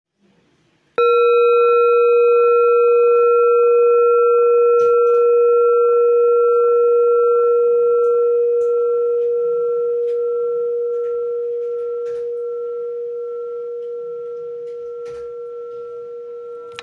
Jambati Singing Bowl Singing Bowl, Buddhist Hand Beaten, Antique Finishing, Jhumkabati, Select Accessories
Material Seven Bronze Metal
It can discharge an exceptionally low dependable tone.